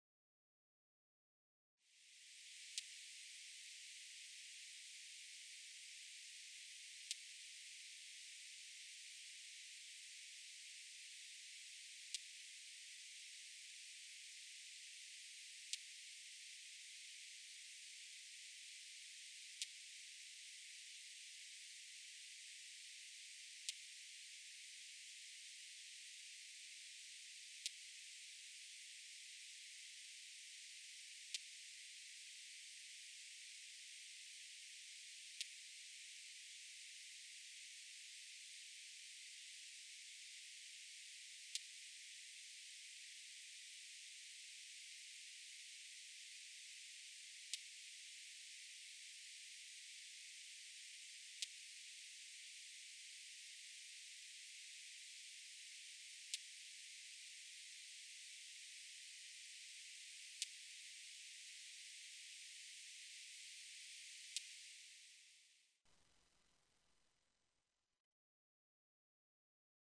Krumknivgræshoppe - Leptophyes punctatissima
Art|Insekter
krumknivgræshoppe.mp3